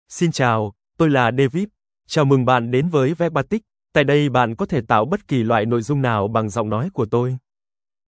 David — Male Vietnamese (Vietnam) AI Voice | TTS, Voice Cloning & Video | Verbatik AI
David is a male AI voice for Vietnamese (Vietnam).
Voice sample
Listen to David's male Vietnamese voice.
Male
David delivers clear pronunciation with authentic Vietnam Vietnamese intonation, making your content sound professionally produced.